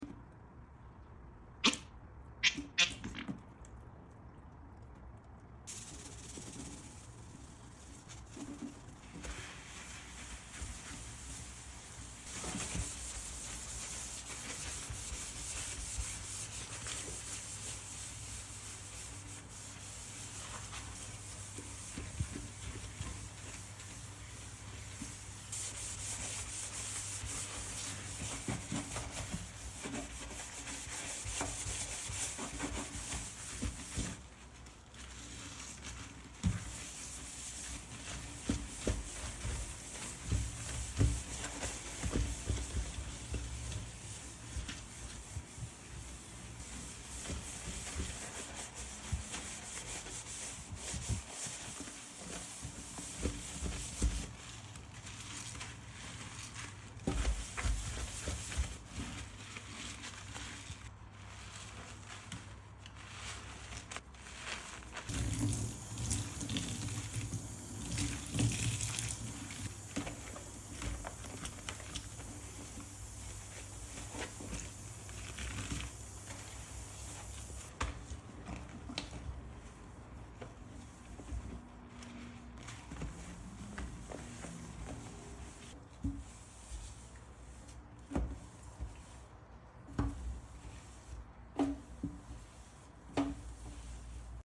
Kitchen Sink Cleaning ASMR – Sound Effects Free Download